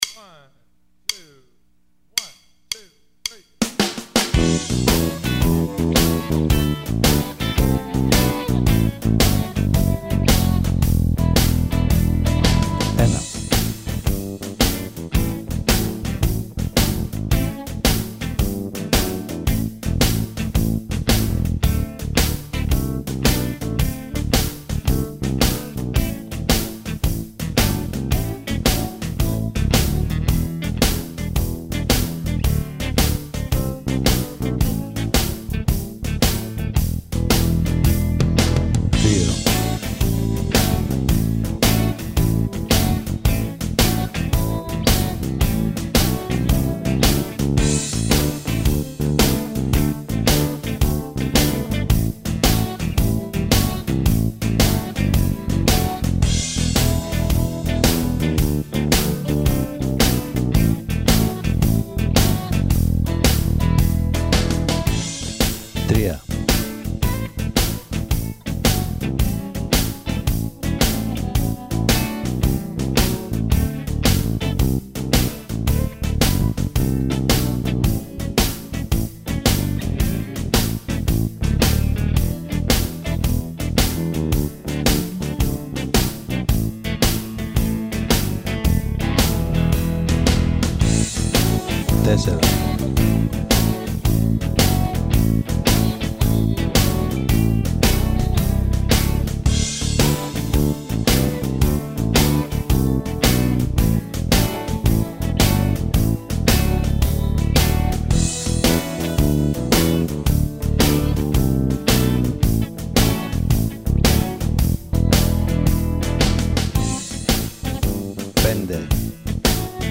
Η χαρά αρχίζει στο 1.03 noizjam.mp3 Attachments noizjam.mp3 noizjam.mp3 1.6 MB